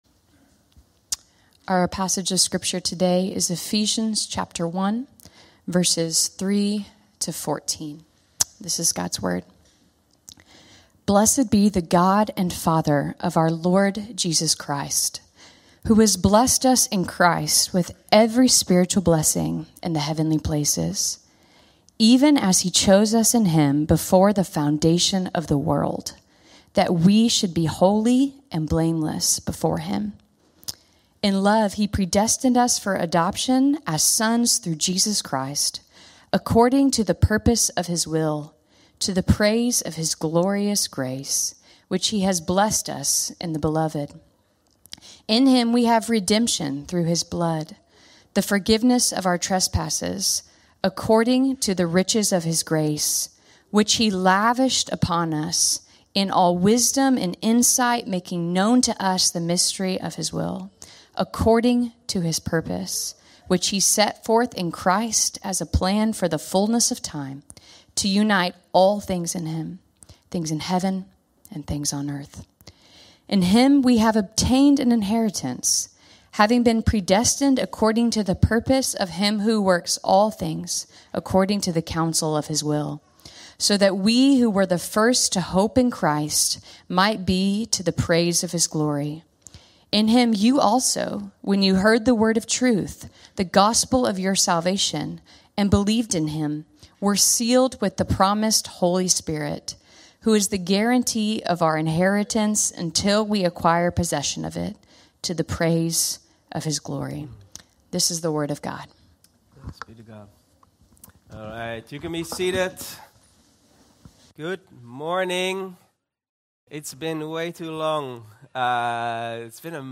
Vineyard Groningen Sermons Identity | Part 1 | Who Am I?